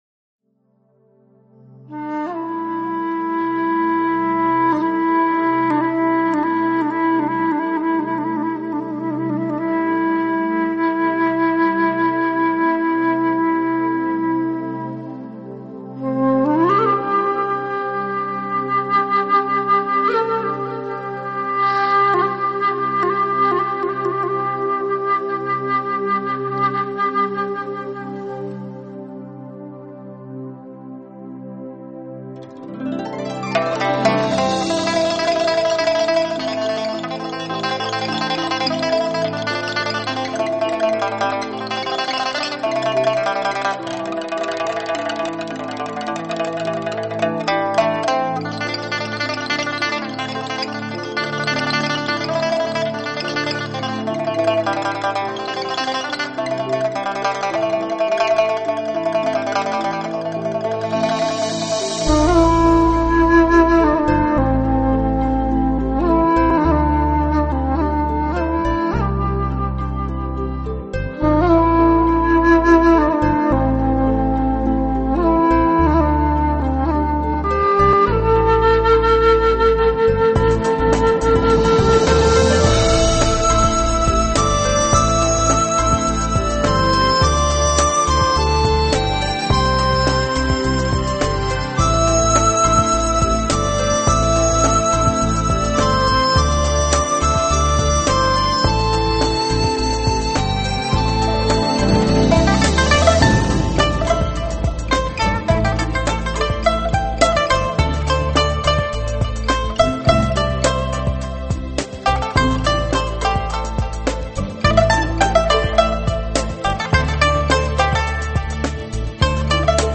音乐类型：民乐